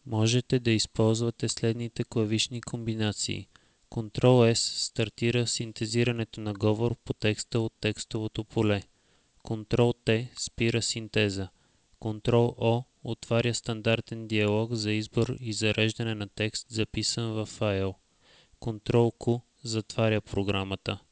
- An audio file, you can listen me reading the first part of the FWTalker help in Bulgarian (330KB)
SpeechExample.au